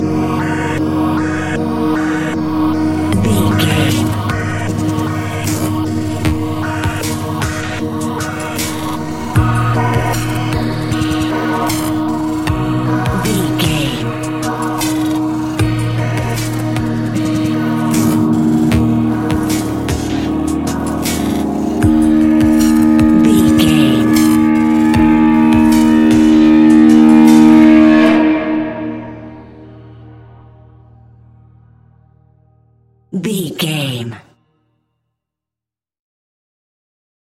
Epic / Action
Fast paced
In-crescendo
Ionian/Major
D♯
industrial
dark ambient
EBM
synths